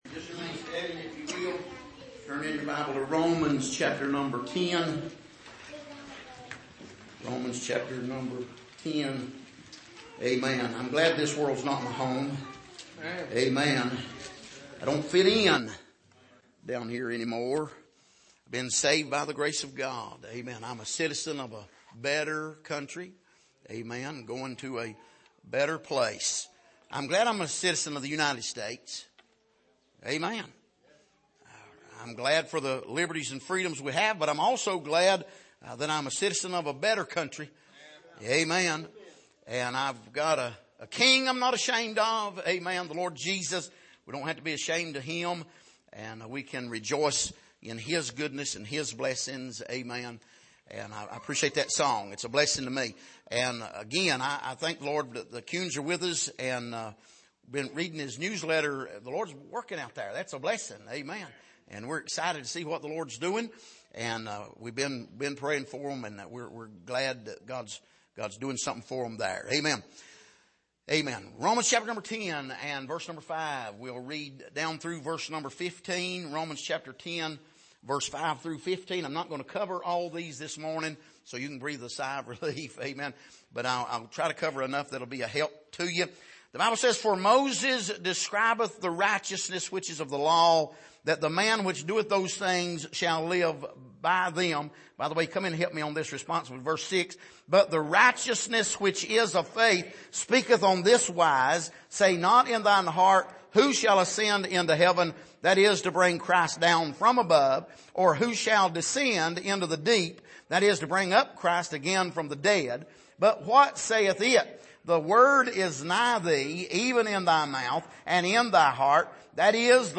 Passage: Romans 8:5-15 Service: Sunday Morning